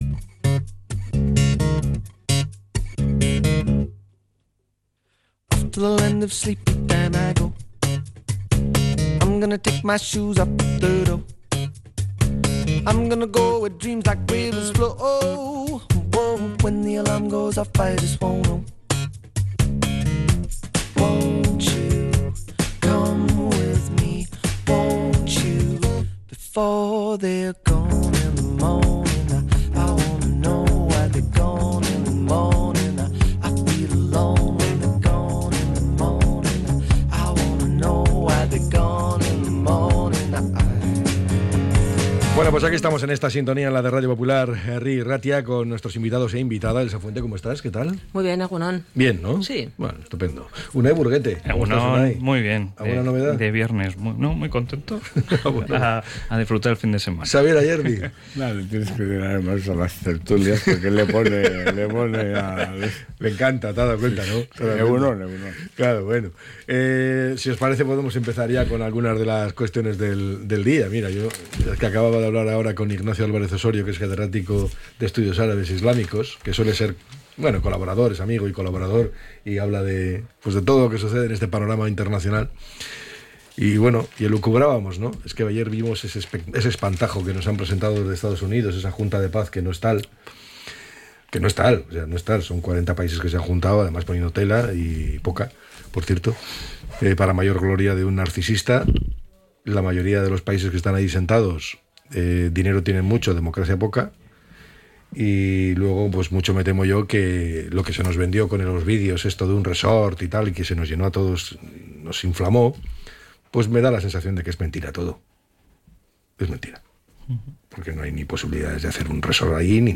La Tertulia 20-02-26.